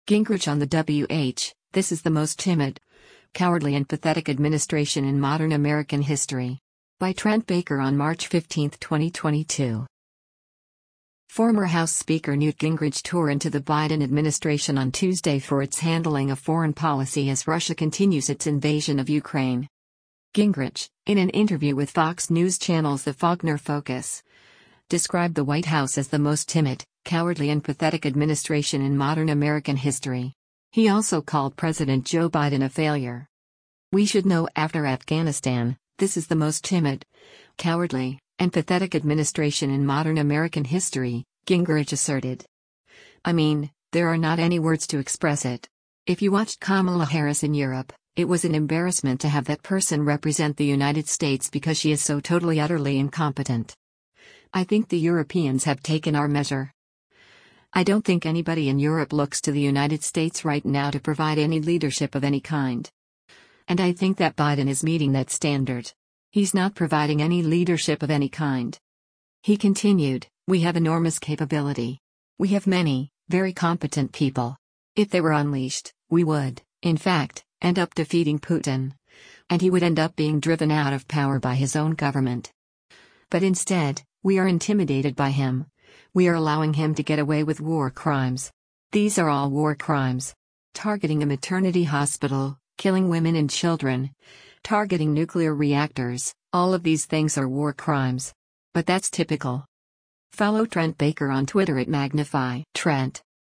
Gingrich, in an interview with Fox News Channel’s “The Faulkner Focus,” described the White House as “the most timid, cowardly and pathetic administration in modern American history.”